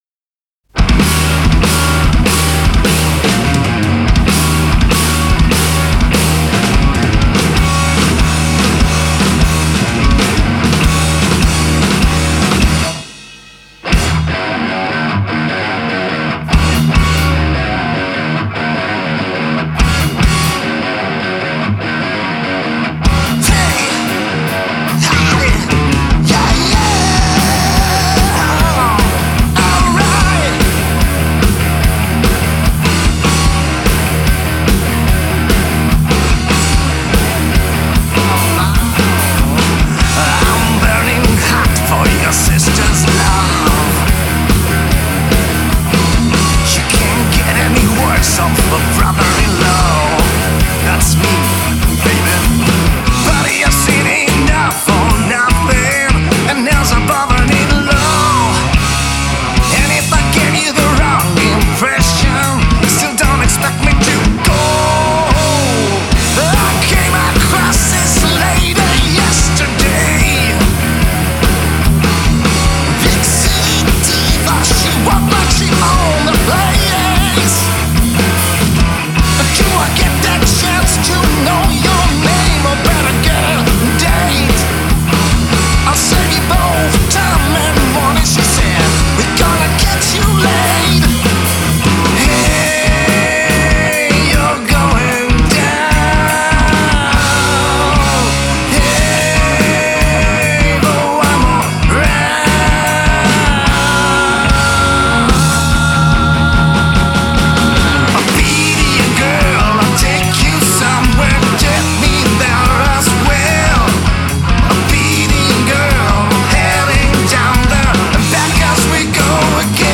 urban hard rocking ensemble
on the guitar
on the drums
bass
on the vocals.